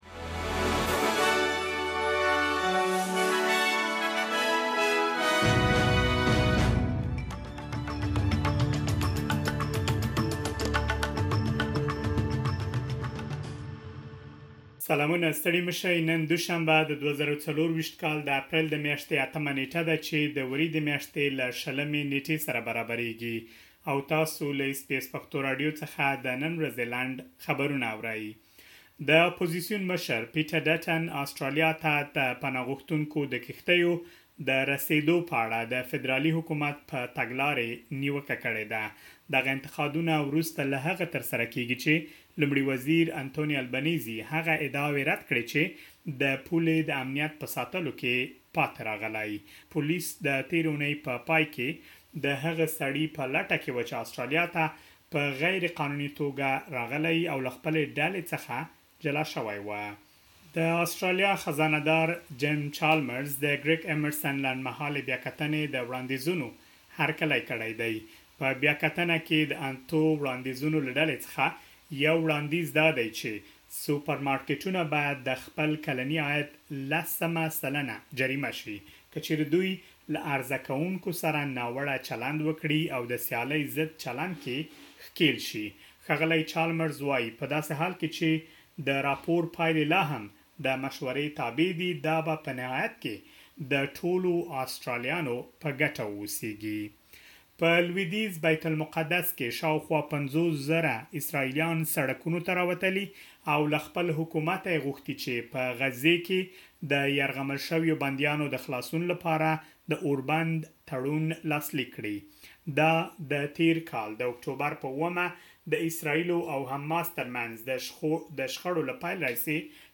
د اس بي اس پښتو راډیو د نن ورځې لنډ خبرونه|۸ اپریل ۲۰۲۴